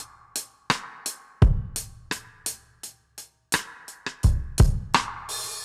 Index of /musicradar/dub-drums-samples/85bpm
Db_DrumsA_Wet_85_02.wav